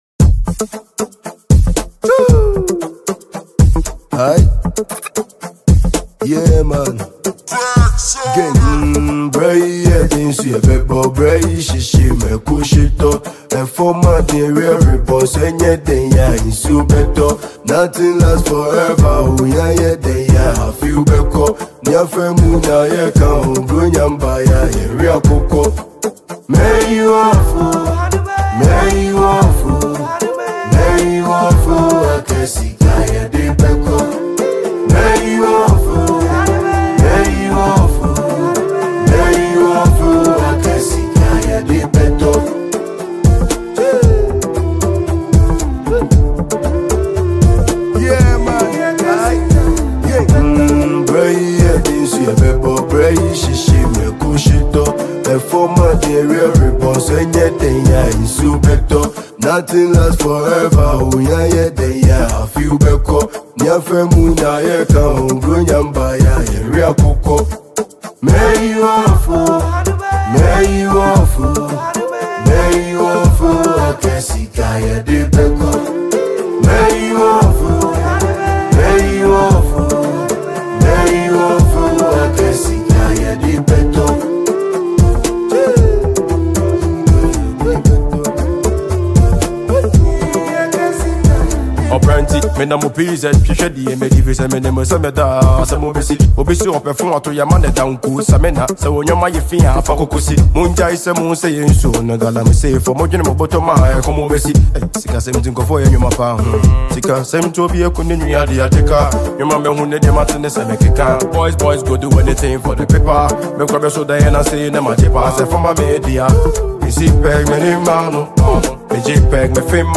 Kumasi-based hip-hop artist and drill artist